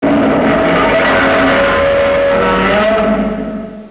Or, you can crank up the bass on your speakers, and listen to
his roar.